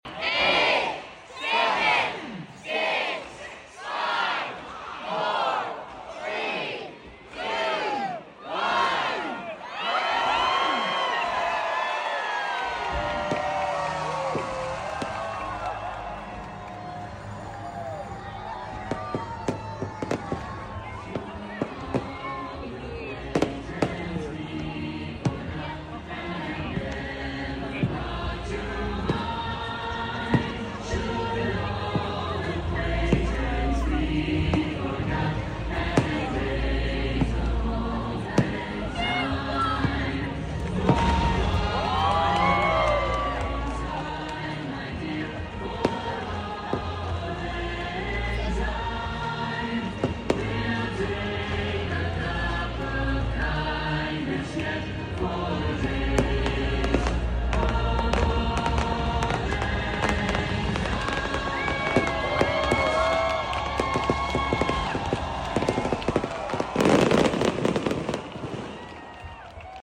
from Hollywood Studios WDW